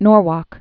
(nôrwôk)